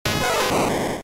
Cri de Pikachu K.O. dans Pokémon Diamant et Perle.